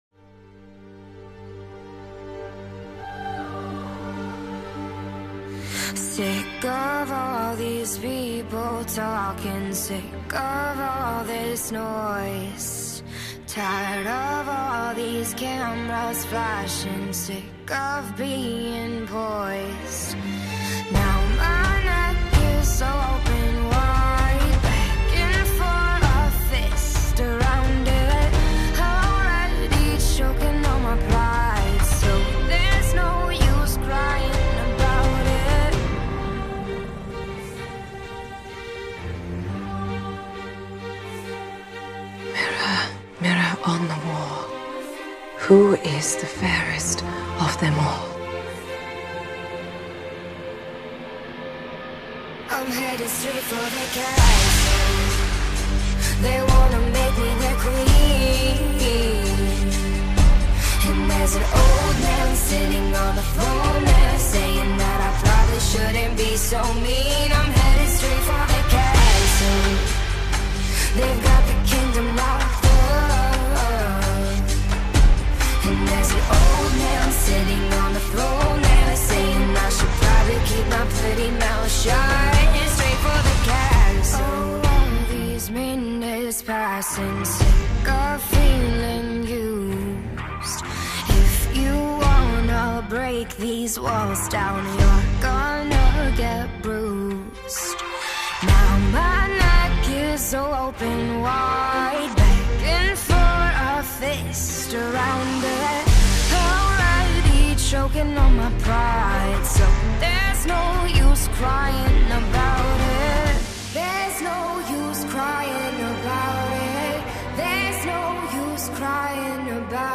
Electropop, AlternativePop